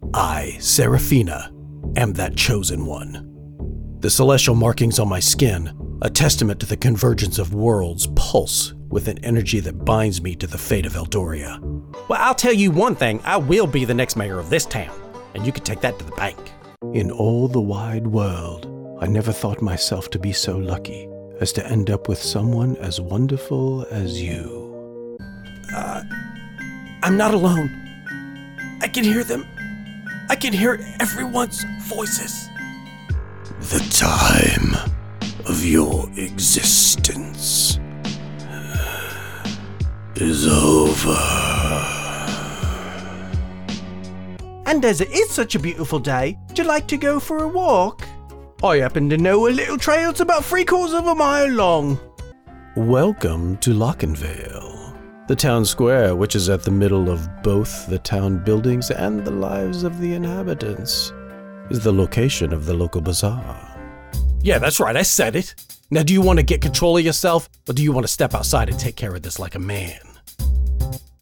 Character Voices Sample